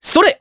Evan-Vox_Attack3_jp_b.wav